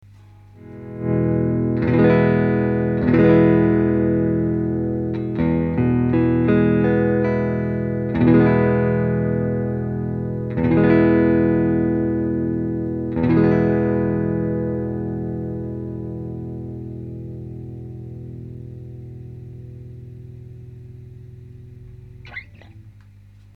ストラトだば。
PUは普通のUSAヴィンテージだば。
とりあえず比較の基準にする音だば。
■アンプ：Fender　Pro-Junior（15W)
■マイク：Seide　PC-VT3000/SHURE　SM57-LCE